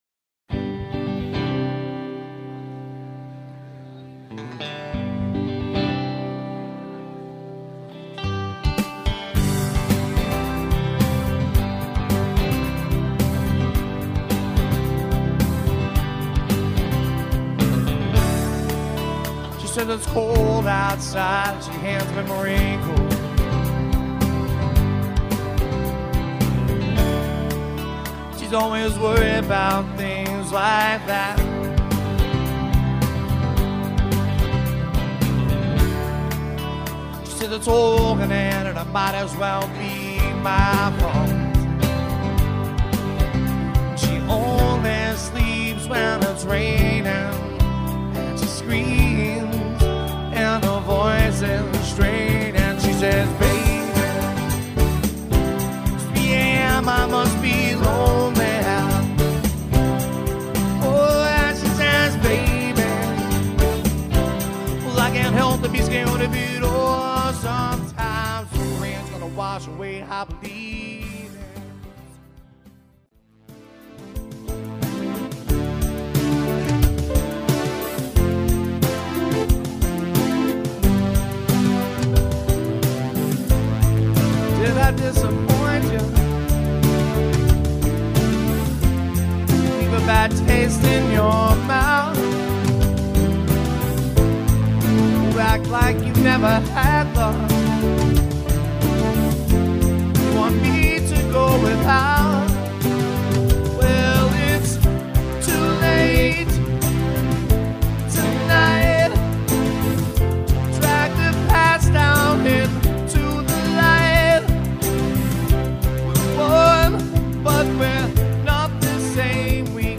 Adult Alternative
The mix may not always be perfect, you may here some
minor distortion, you will here some background noise.